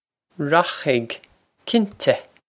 Pronunciation for how to say
Rokh-ig, kin-teh!
This is an approximate phonetic pronunciation of the phrase.